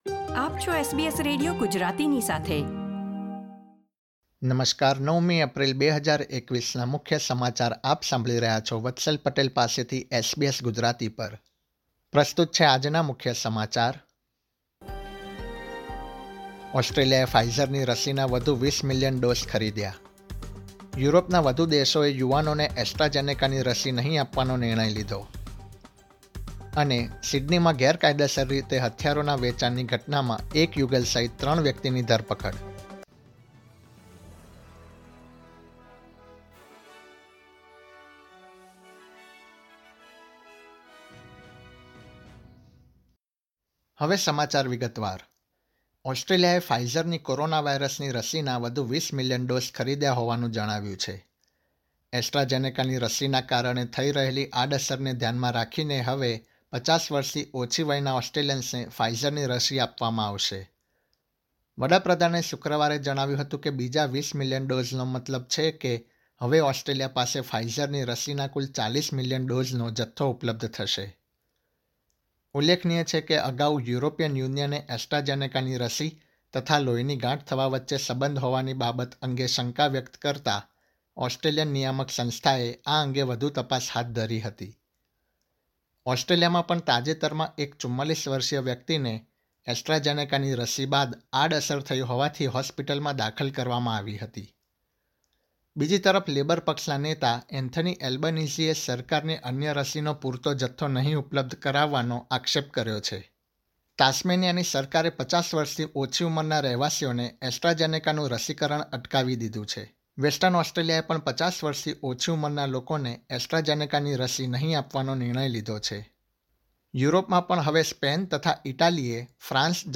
gujarati_0904_newsbulletin.mp3